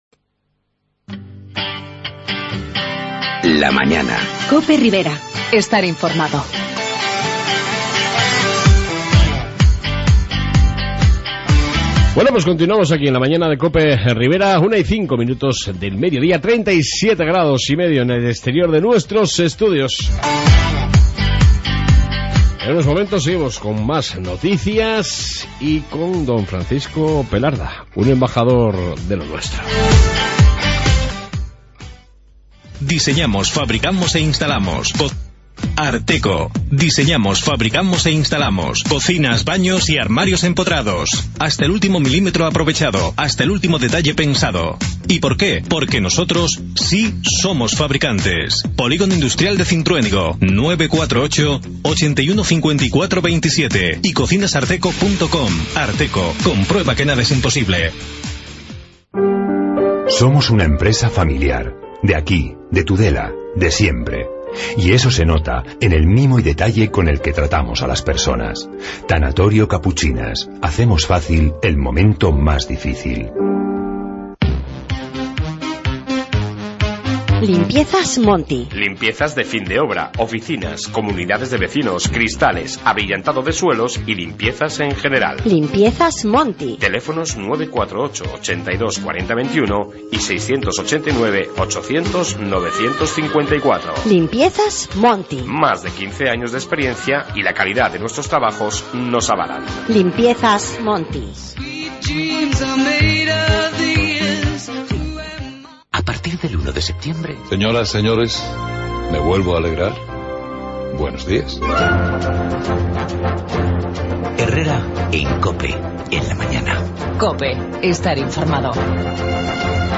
AUDIO: Noticias Riberas y entrevista